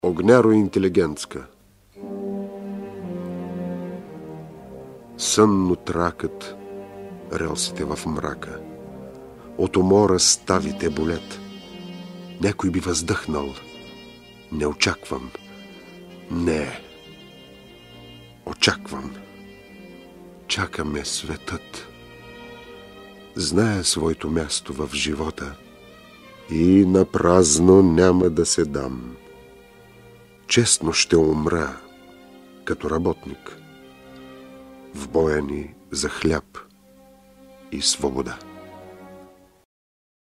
На своите другари от железниците Вапцаров посвещава „Огняроинтелигентска“ (изпълнена от Димитър Буйнозов):